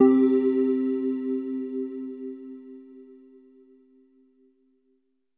LEAD C3.wav